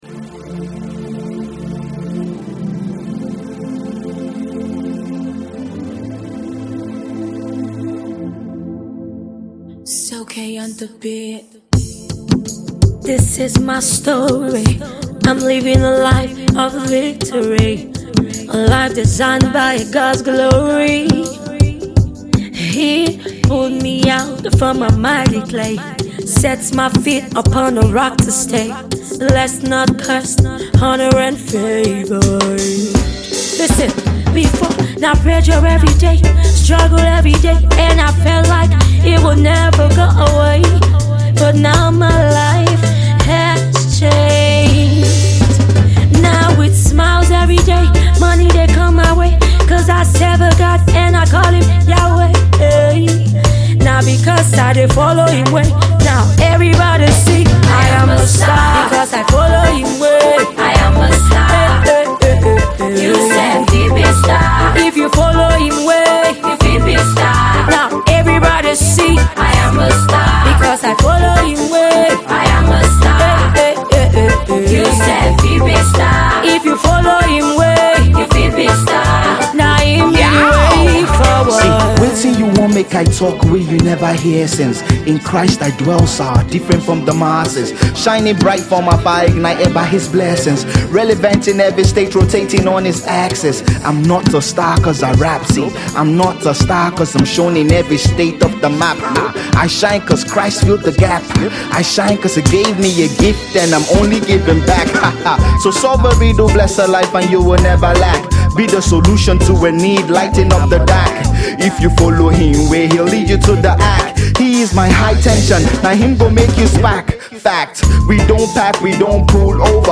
gospel rap